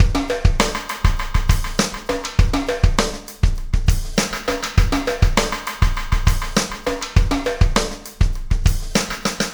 Pulsar Beat 06.wav